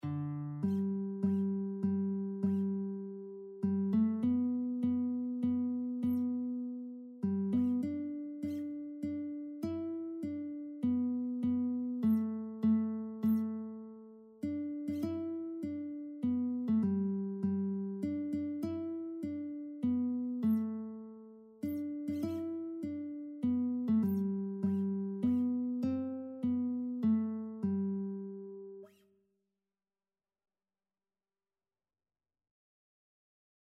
Christian Christian Lead Sheets Sheet Music Hallelujah!
G major (Sounding Pitch) (View more G major Music for Lead Sheets )
3/4 (View more 3/4 Music)
Classical (View more Classical Lead Sheets Music)